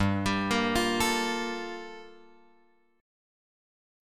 Gm9 chord {3 x 5 3 6 5} chord